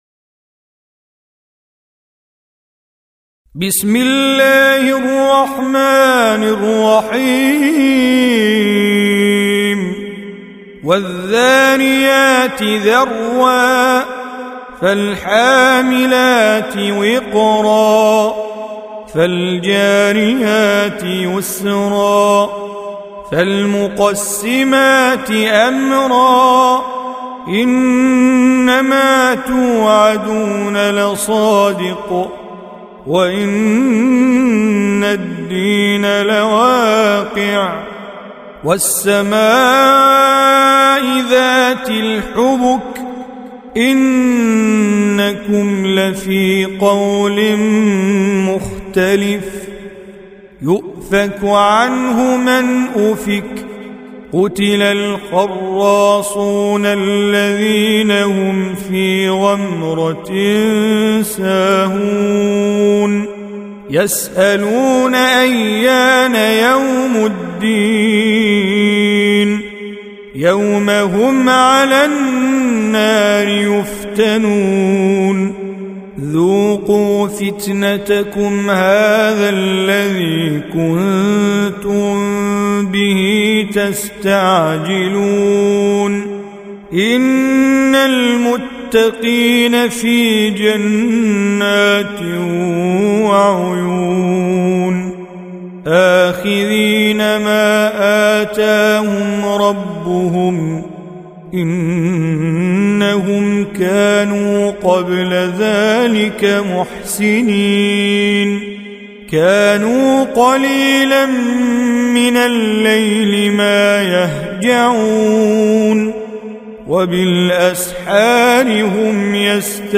51. Surah Az-Z�riy�t سورة الذاريات Audio Quran Tajweed Recitation
Surah Repeating تكرار السورة Download Surah حمّل السورة Reciting Mujawwadah Audio for 51.